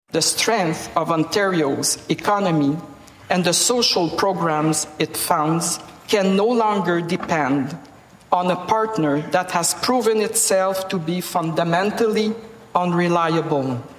Delivered by Lt.-Gov. Edith Dumont, the speech blamed U.S. tariffs and protectionism for economic uncertainty and emphasized building a more competitive Ontario.
speech.mp3